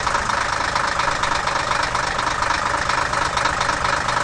Truck, Hino Diesel, Idle Loop